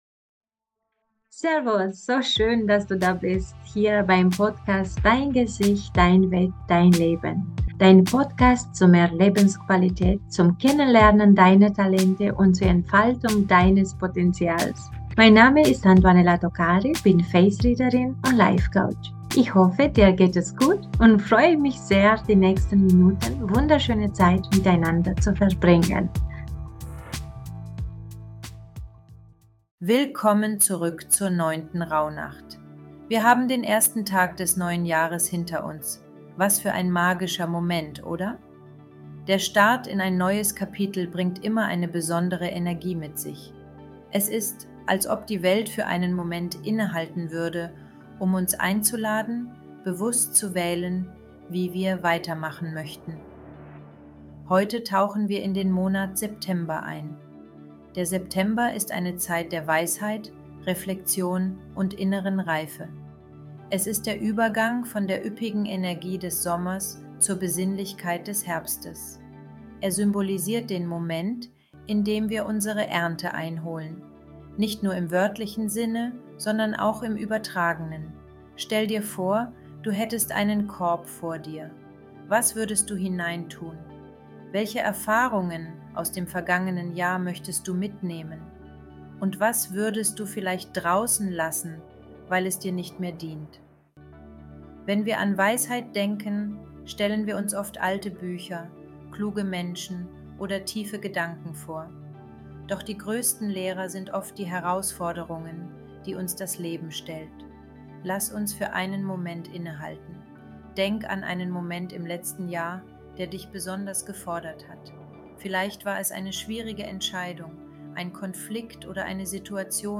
Eine Meditation für innere Klarheit und Ruhe